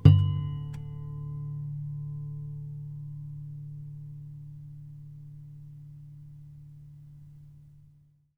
strings_harmonics
harmonic-11.wav